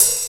22 OP HAT.wav